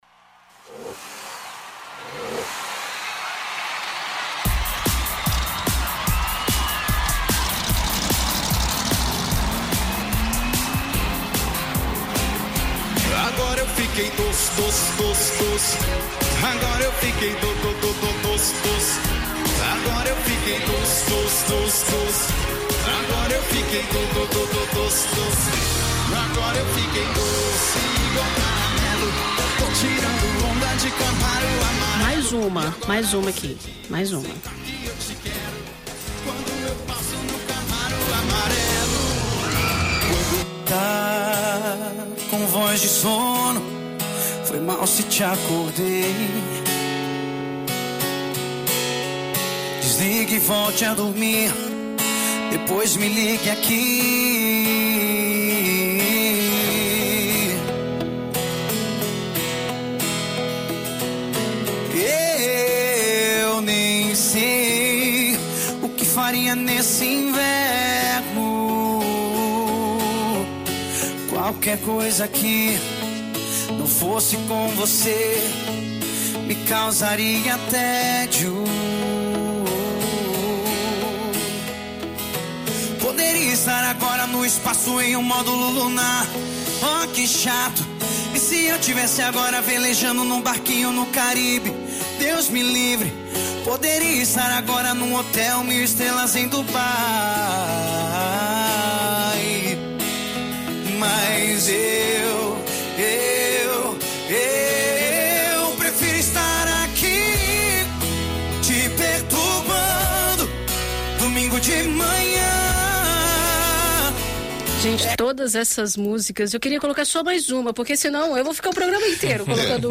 Em entrevista a BandNews FM Espírito Santo